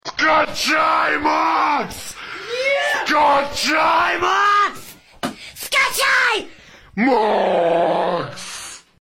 Moaning Sound Effects MP3 Download Free - Quick Sounds